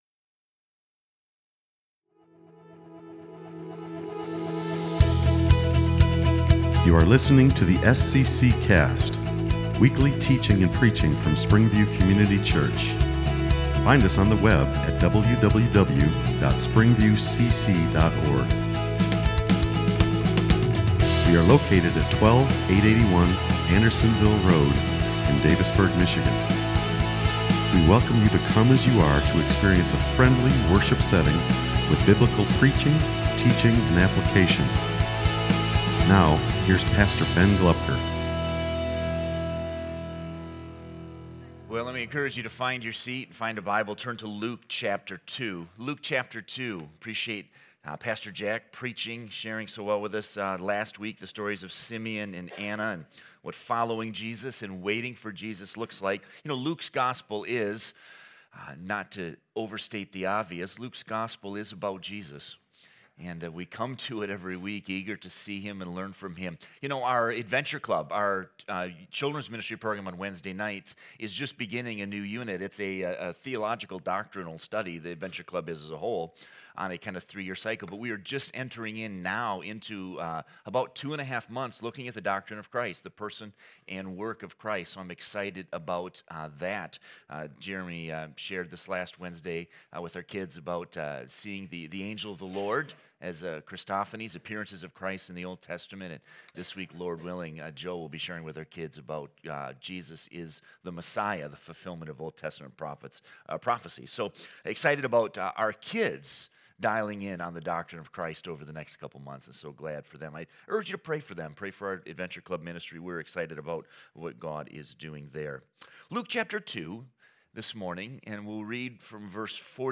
Sermons | SPRINGVIEW COMMUNITY CHURCH